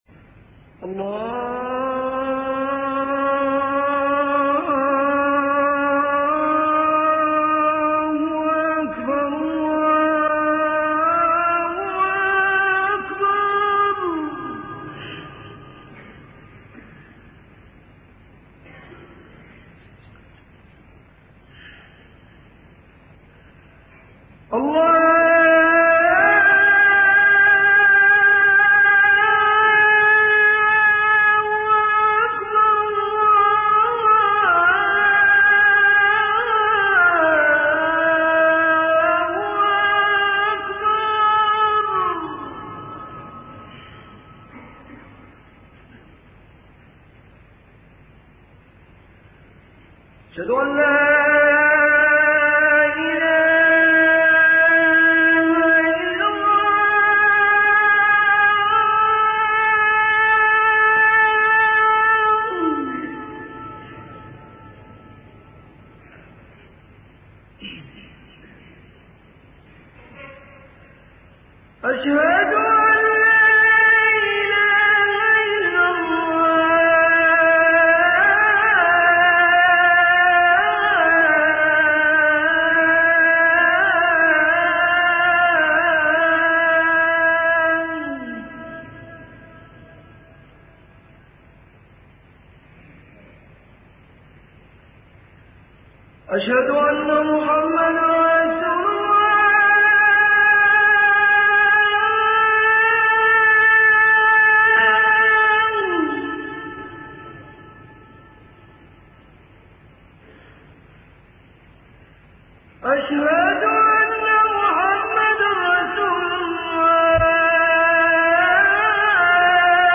أناشيد ونغمات
عنوان المادة أذن الحرم المكي - 3